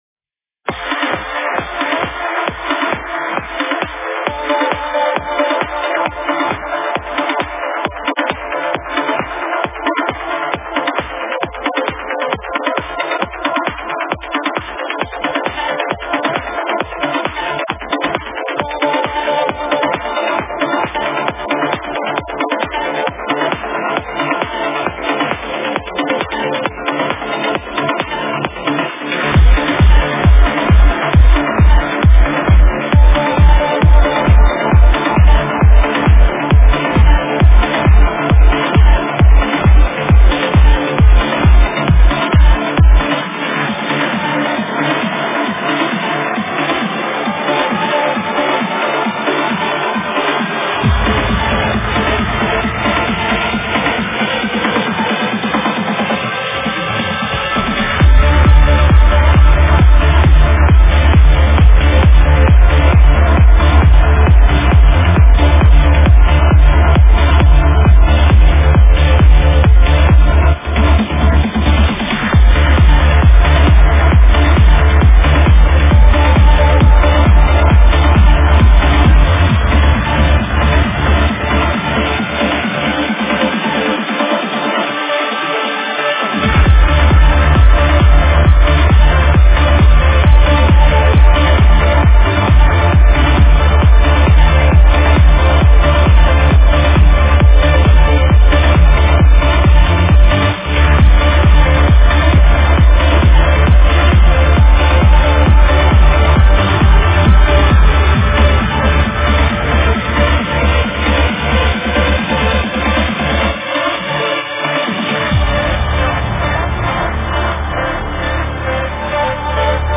Стиль: Trance